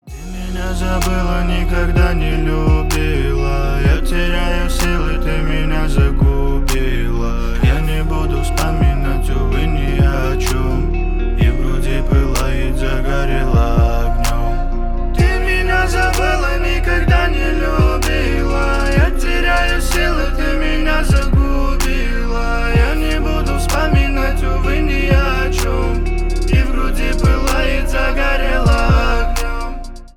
Рэп и Хип Хоп
грустные
спокойные